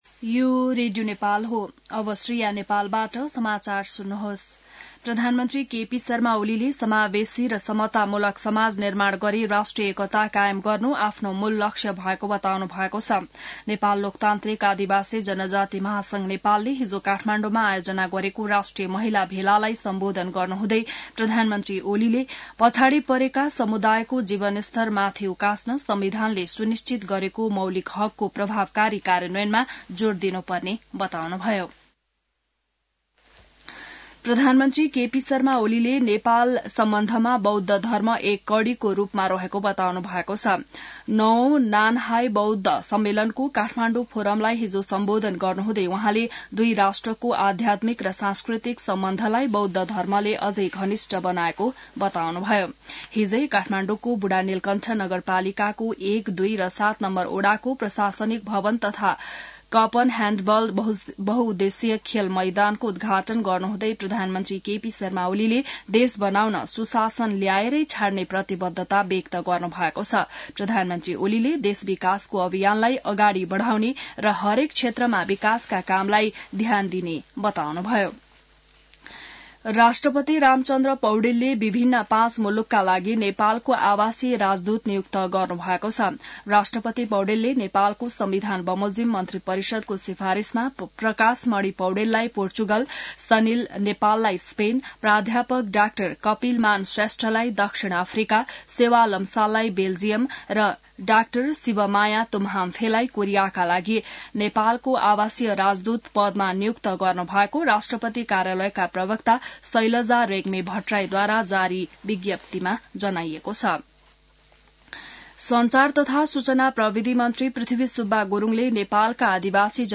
An online outlet of Nepal's national radio broadcaster
बिहान ६ बजेको नेपाली समाचार : ३० मंसिर , २०८१